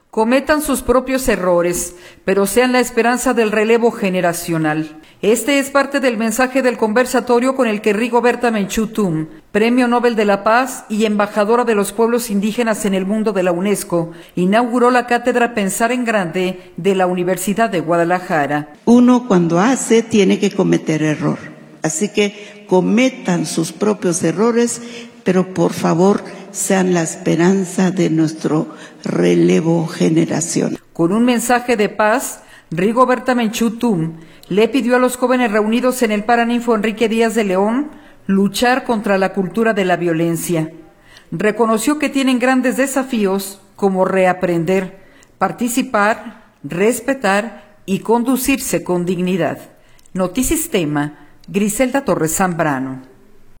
Cometan sus propios errores, pero sean la esperanza del relevo generacional, este es parte del mensaje del conversatorio con el que Rigoberta Menchú Tum, Premio Nobel de la Paz y embajadora de los pueblos indígenas en el Mundo de la Unesco, inauguró la cátedra “Pensar en Grande” de la Universidad de Guadalajara.